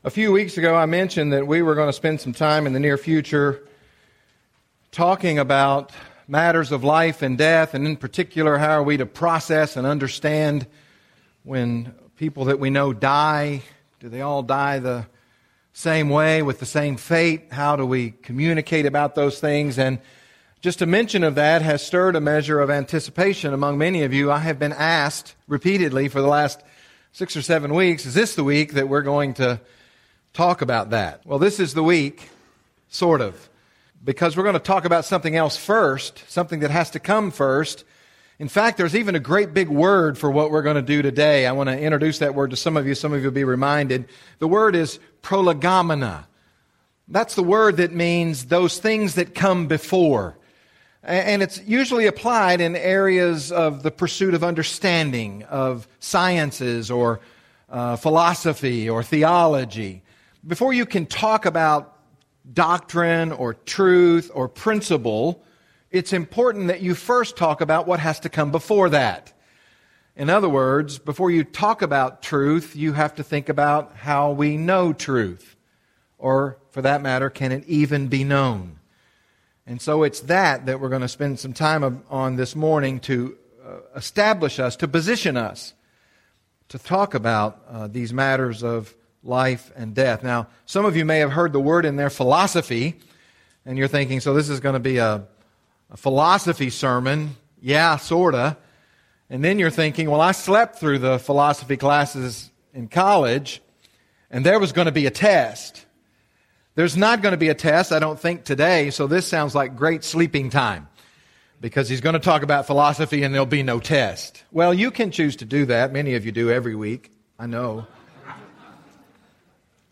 The Truth About Life and Death | SermonAudio Broadcaster is Live View the Live Stream Share this sermon Disabled by adblocker Copy URL Copied!